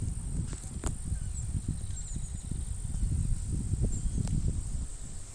Yellow-chinned Spinetail (Certhiaxis cinnamomeus)
Country: Argentina
Detailed location: Dique Río Hondo
Condition: Wild
Certainty: Recorded vocal